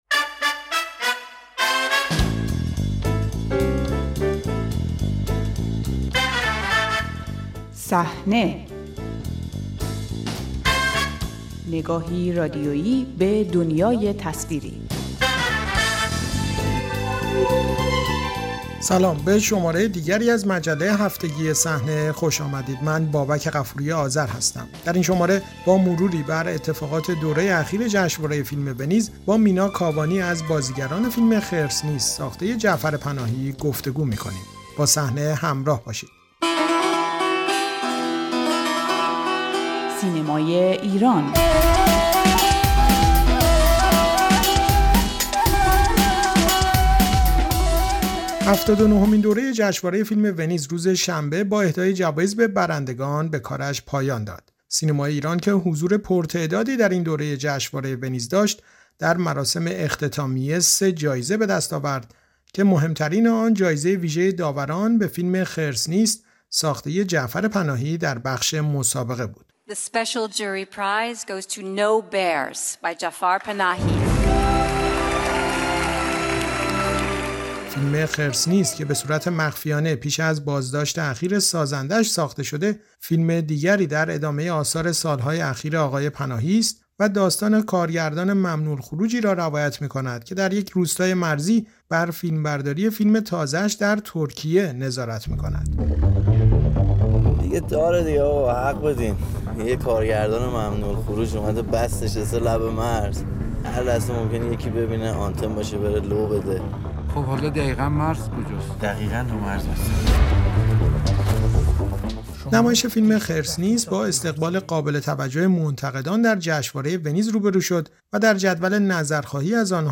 گفت‌وگو با مینا کاوانی، بازیگر فیلم «خرس نیست»